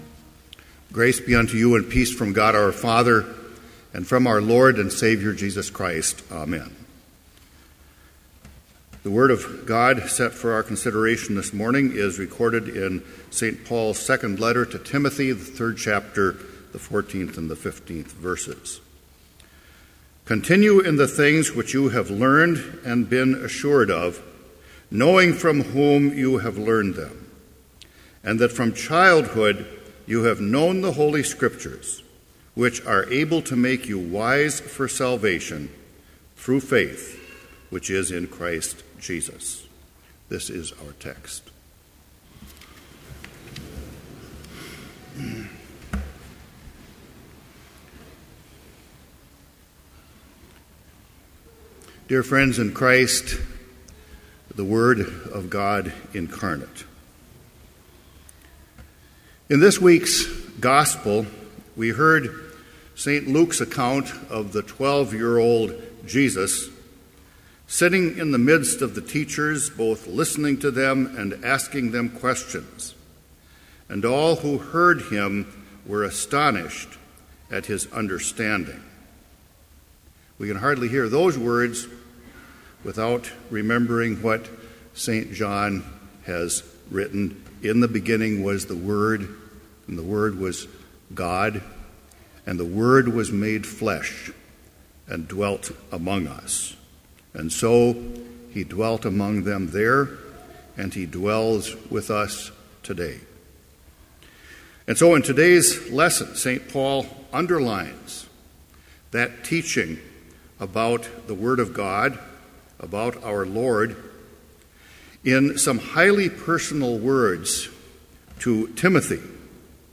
Complete service audio for Chapel - January 16, 2014
Order of Service Prelude Hymn 181, vv. 1-4, Of the Father's Love Begotten Reading: 2 Timothy 3:14-15 Homily Prayer Hymn 181, vv. 5-7, O ye heights of heav'n, adore Him ... Benediction Postlude